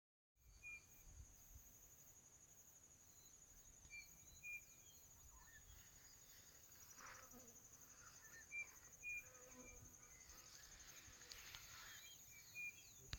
Crespín (Tapera naevia)
Nombre en inglés: Striped Cuckoo
Fase de la vida: Adulto
Localidad o área protegida: Isla Talavera
Condición: Silvestre
Certeza: Vocalización Grabada